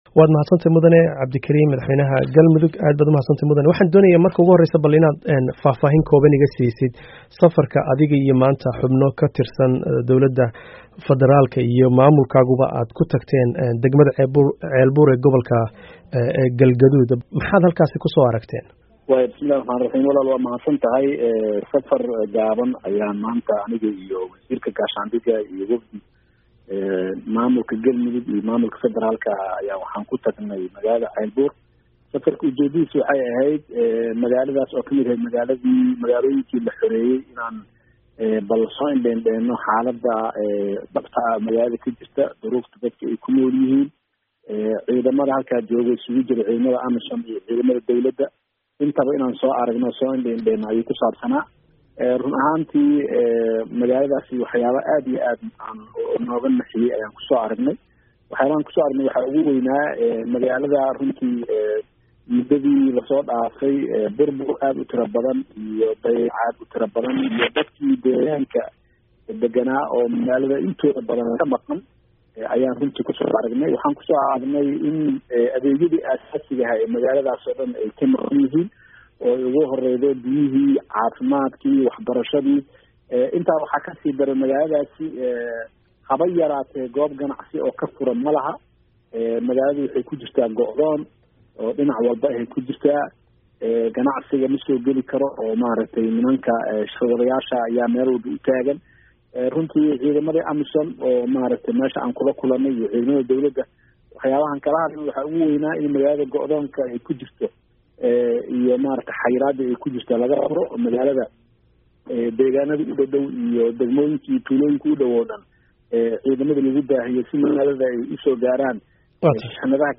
Dhageyso Wareysiga Madaxweynaha Galmudug